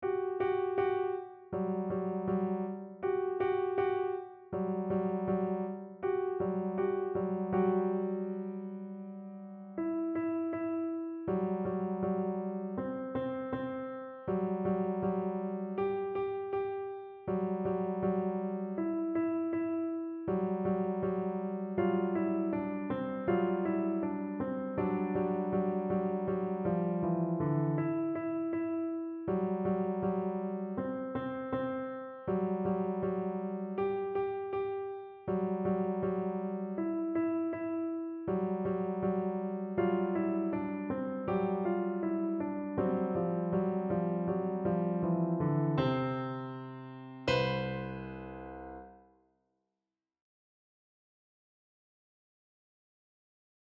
for Beginner Level